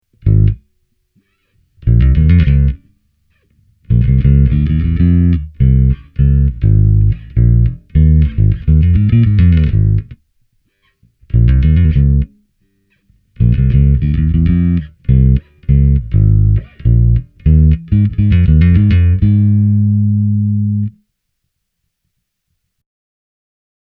I recorded the following soundbites direct, using a Sans Amp Bass Driver DI plugged into a Focusrite Saffire 6 USB -soundcard. Each style has been recorded with one set of Sans Amp settings, taking care to keep the differences between each bass in terms of output level and sound intact.
Kataja 60 P – fingerstyle
As you can clearly hear, the Kataja-basses deliver great, vintage tones.
p-bass-finger.mp3